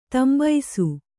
♪ tambaisu